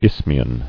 [isth·mi·an]